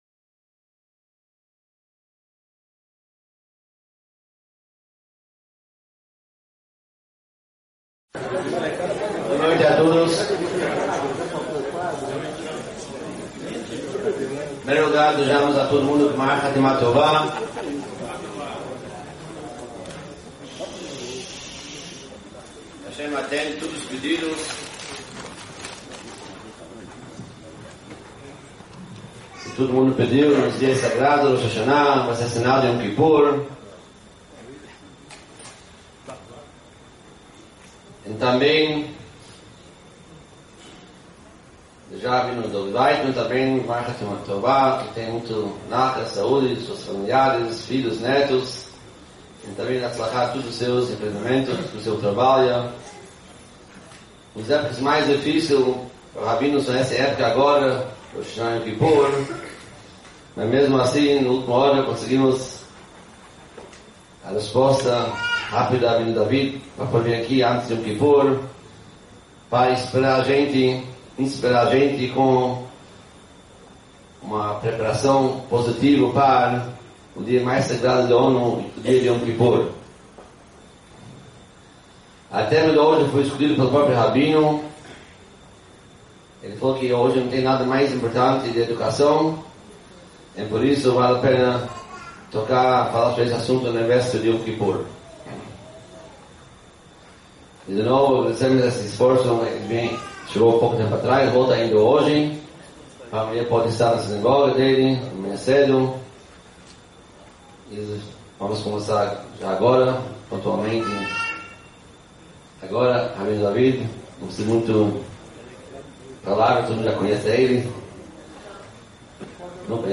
Palestra-As-quatro-joias-que-transformam-nossos-filhos-1.mp3